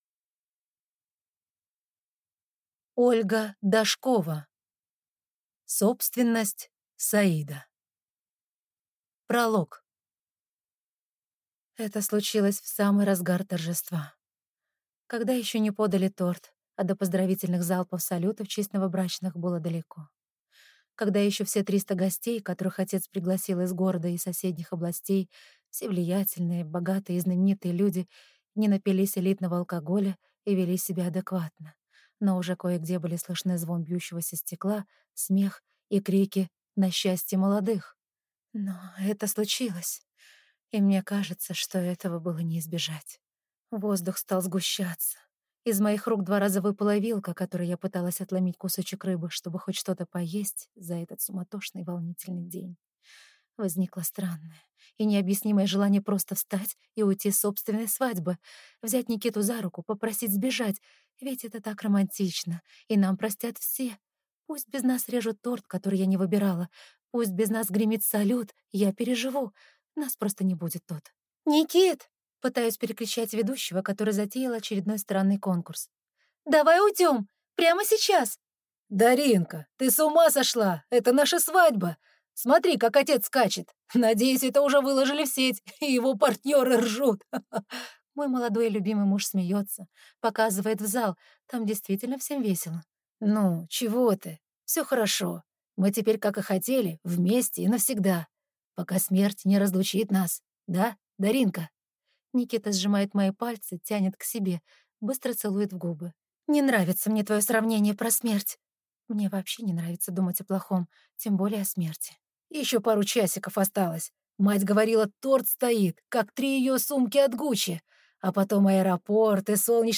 Аудиокнига Собственность Саида | Библиотека аудиокниг